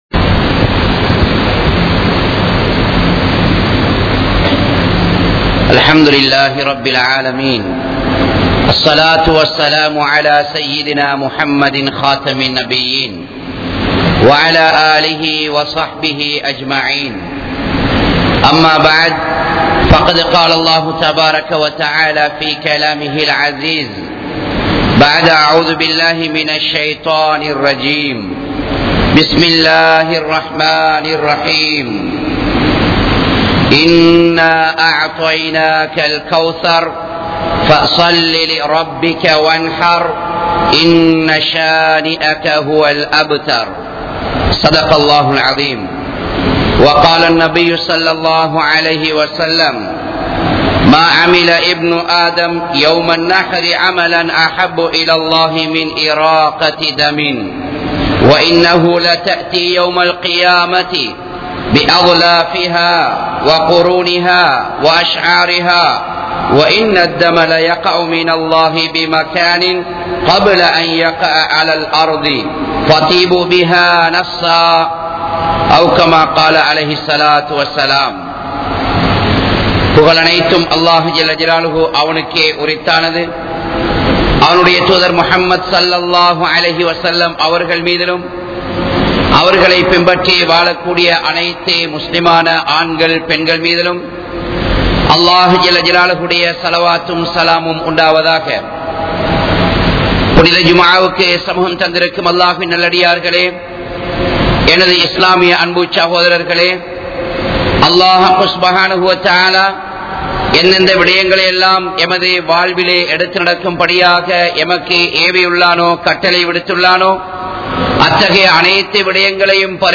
Ulhiya`vin Sirappuhal (உழ்ஹிய்யாவின் சிறப்புகள்) | Audio Bayans | All Ceylon Muslim Youth Community | Addalaichenai
Wekanda Jumuah Masjidh